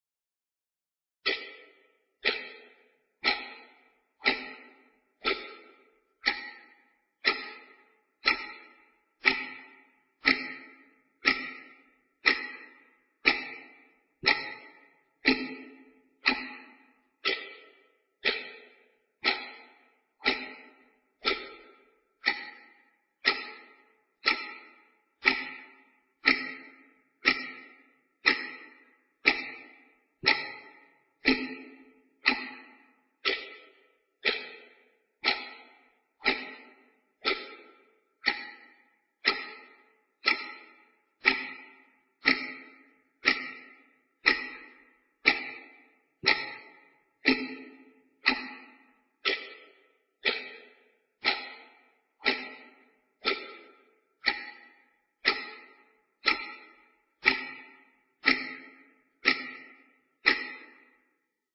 Clock Ticking Sound Effect Free Download
Clock Ticking